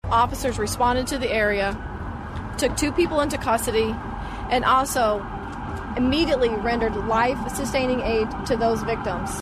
Kansas City authorities held a press conference shortly after the incident.   Kansas City Police Chief Stacey Graves:
Audio courtesy of ABC News.
chief-graves-abc.mp3